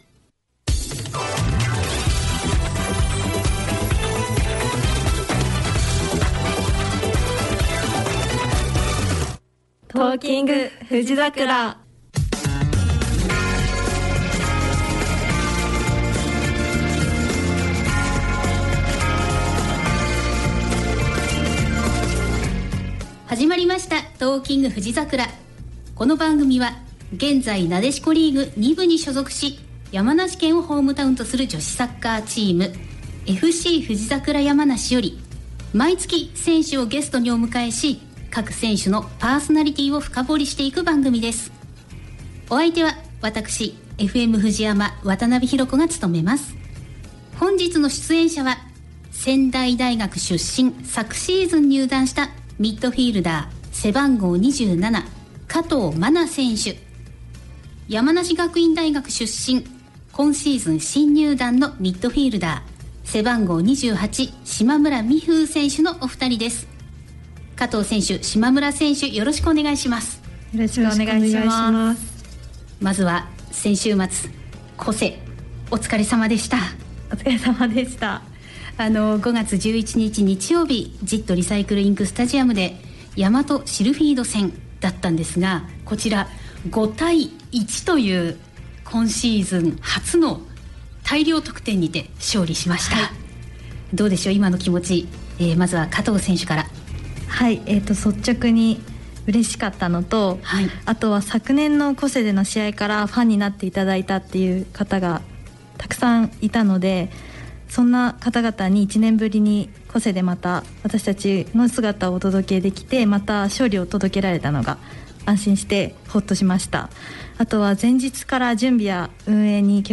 「Talking！ふじざくら」2025年5月15日(木)放送分のアーカイブです。